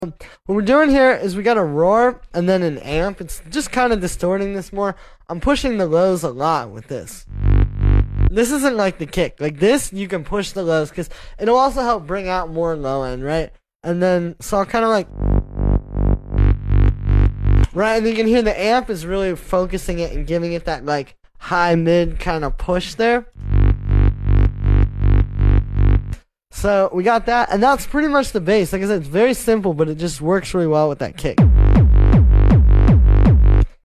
Driving The Bass With Focused Sound Effects Free Download